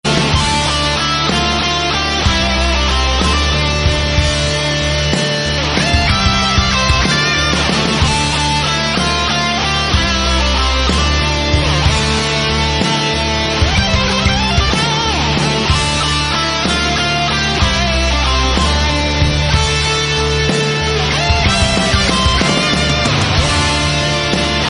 Category: Metal Ringtones Tags